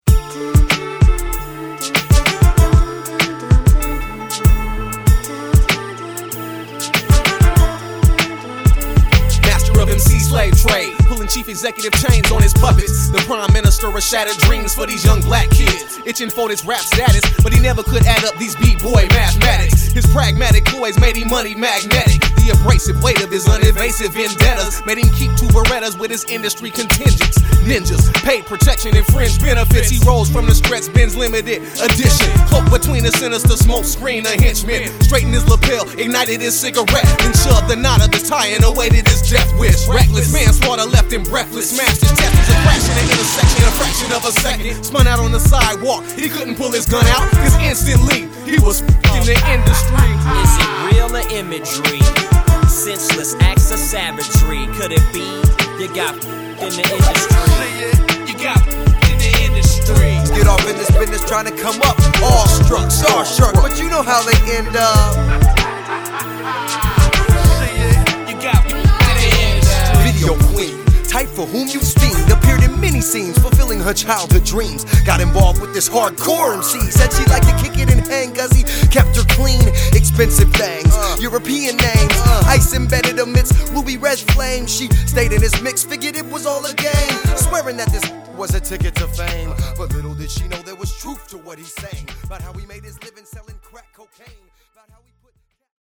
lends scratching talents throughout the record